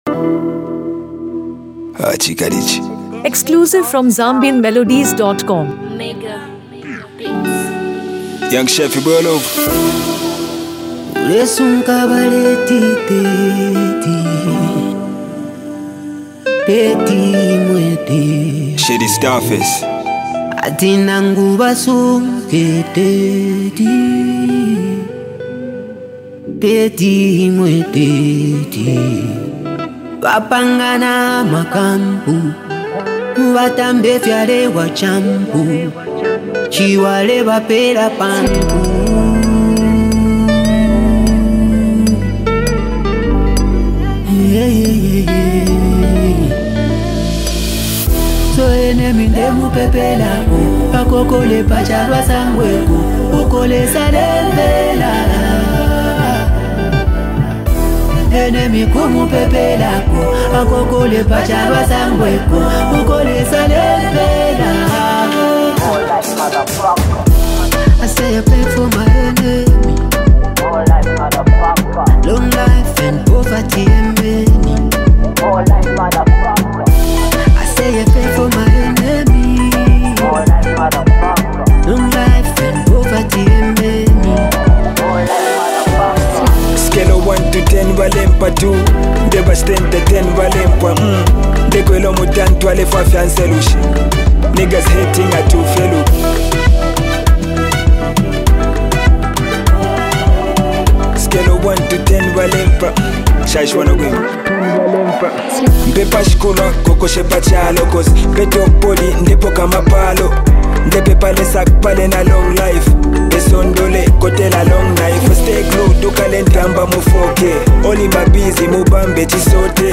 Uplifting Gospel Rap Anthem
a top-tier Zambian rapper with a huge following
With its high-energy beats and spiritual message